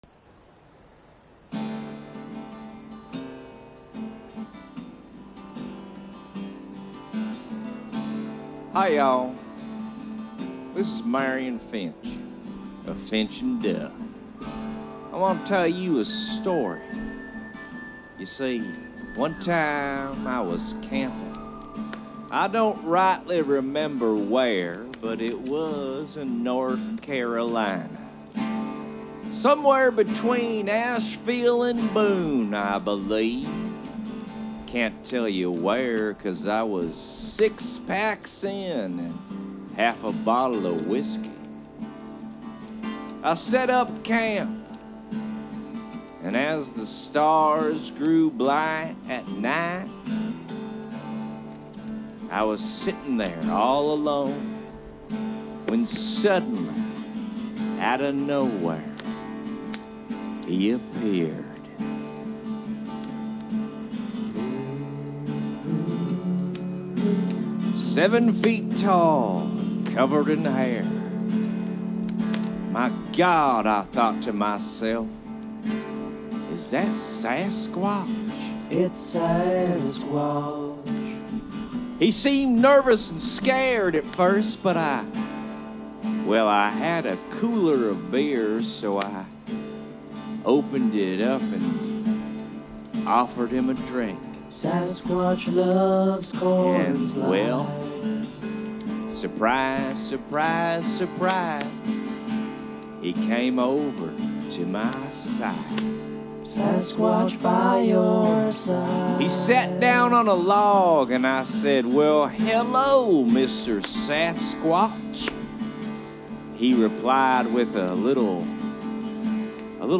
Selection from comedy album I engineered/mixed.